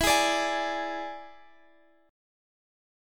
Listen to E7 strummed